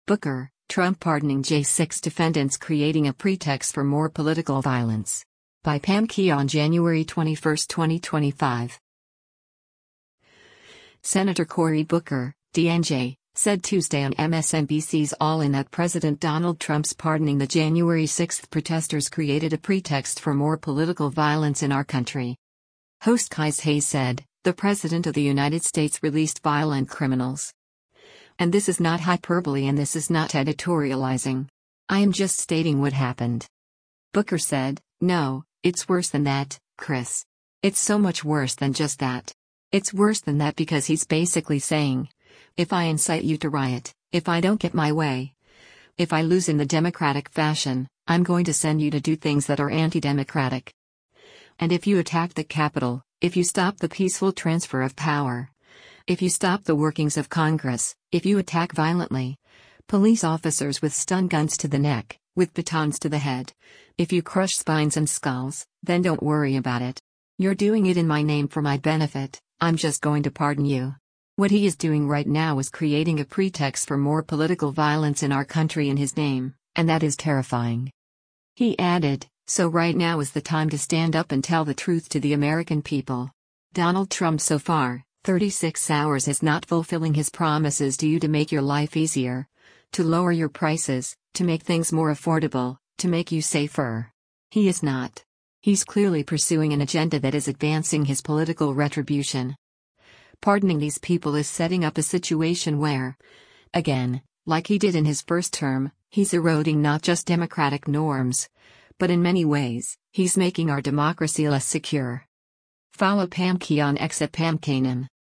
Senator Cory Booker (D-NJ) said Tuesday on MSNBC’s “All In” that President Donald Trump’s pardoning the January 6 protesters created “a pretext for more political violence in our country.”